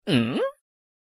抖音男孩疑惑嗯一声音效_人物音效音效配乐_免费素材下载_提案神器
抖音男孩疑惑嗯一声音效免费音频素材下载